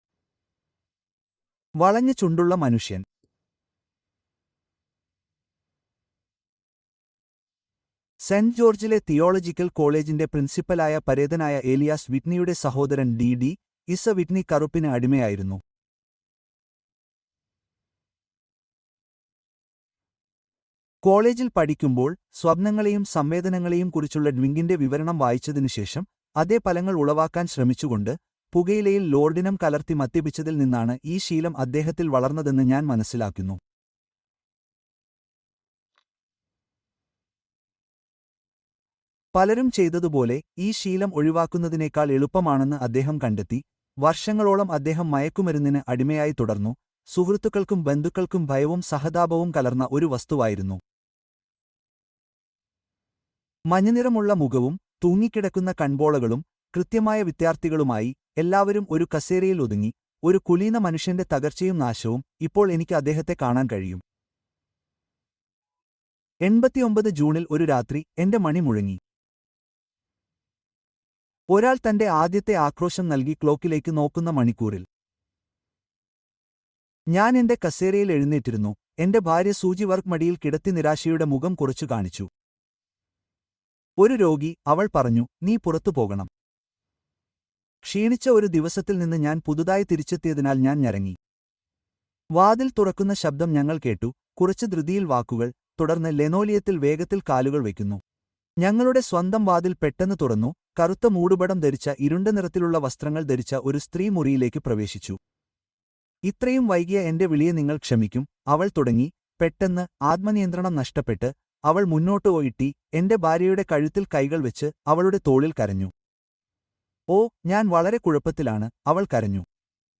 Wisteria Lodge Part Two: Shocking Revelations (Audiobook)